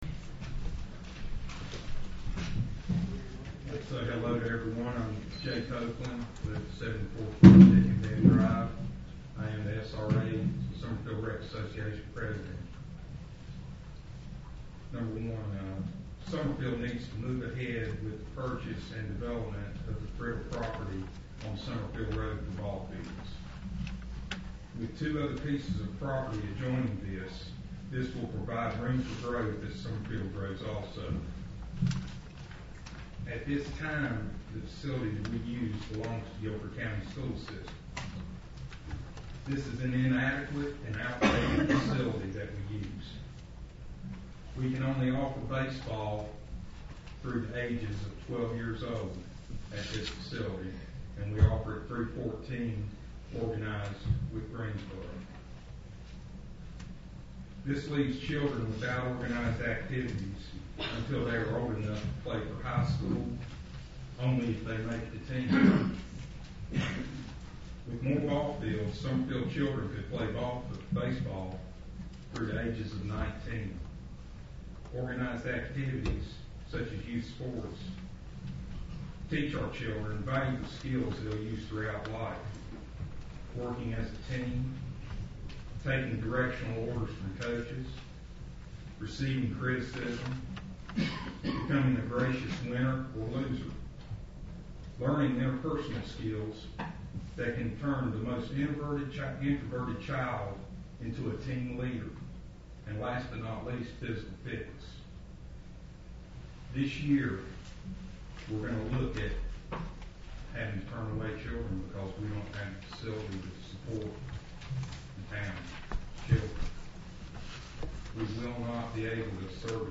2-13-07 Sound recordings from town council meeting
Although not part of the official town recording, they have not been intentionally altered and are presented as is.